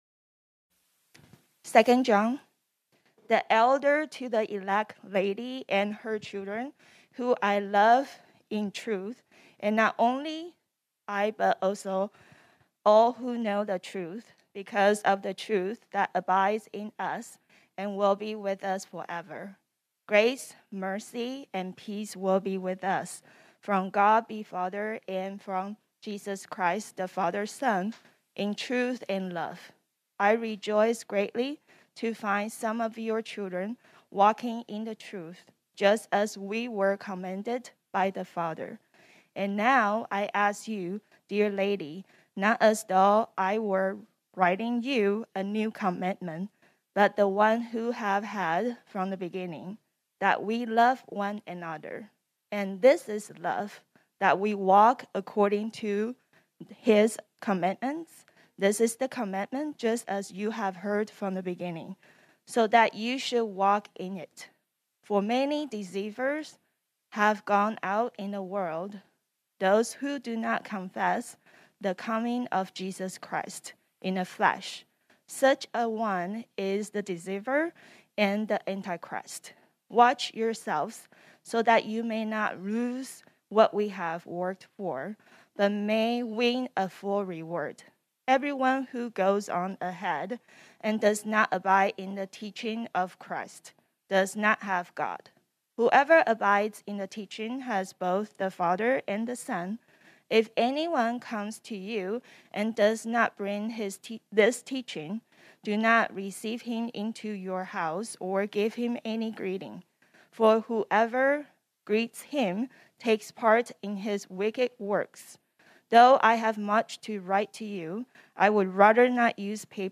This sermon was originally preached on Sunday, March 6, 2022.